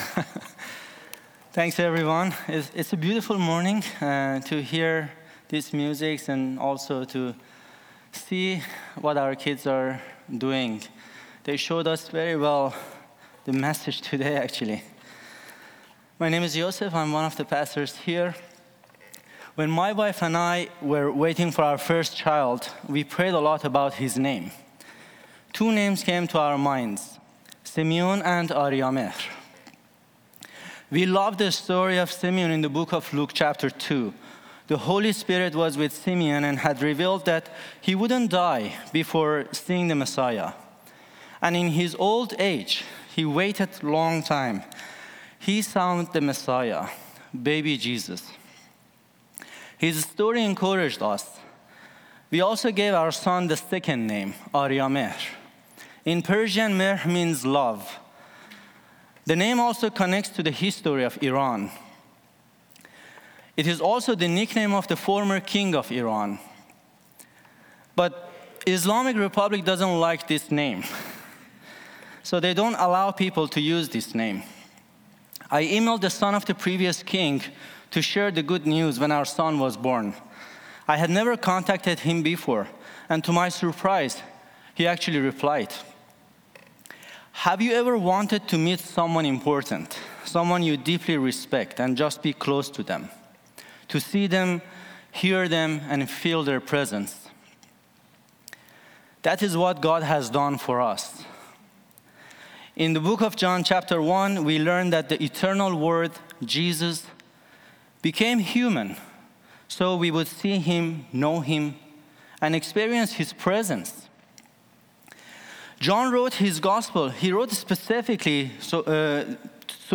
He reminds us to slow down, tune our hearts to God’s voice, and begin the year grounded in Scripture.//Verses and message not…